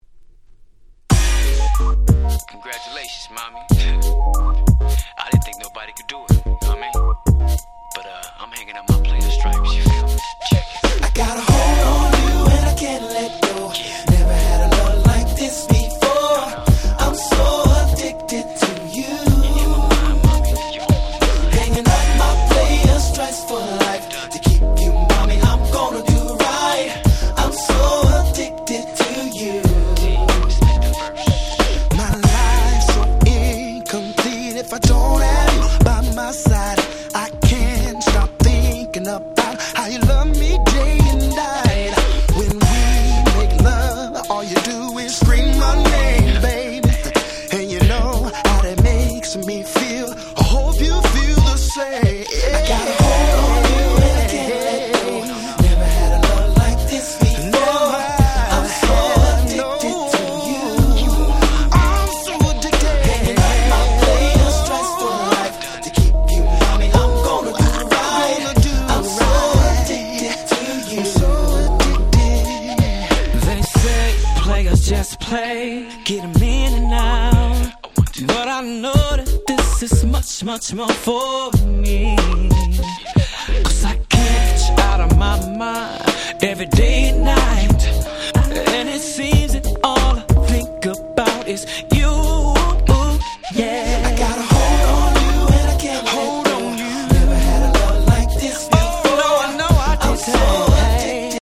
02' マイナーR&B良曲！！